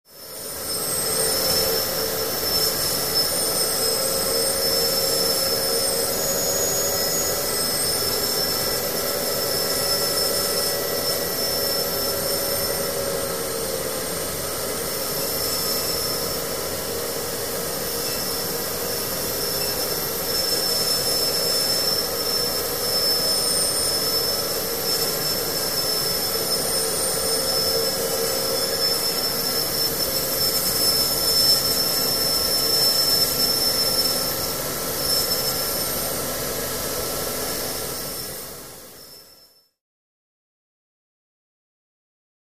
Machine Hum And Whine High And Low Pitch